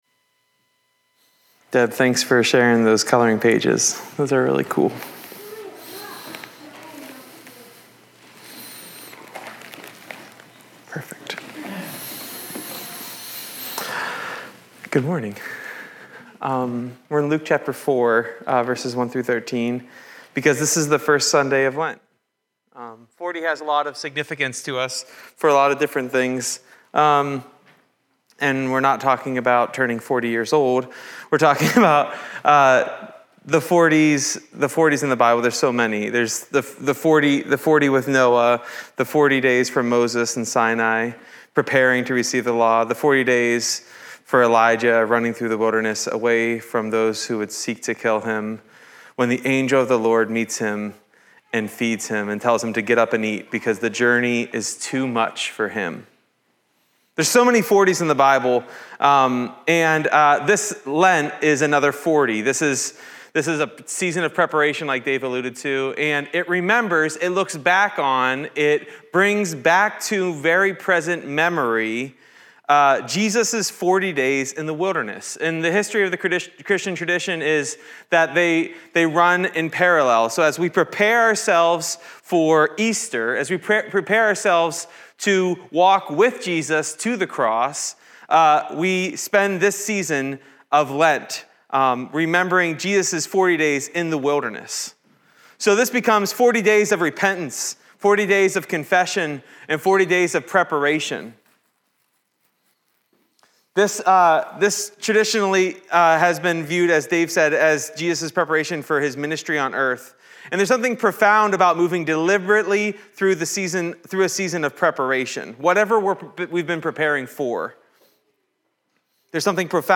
Jeremiah 33:14-16 Order of worship/bulletin Youtube video recording Sermon audio recording.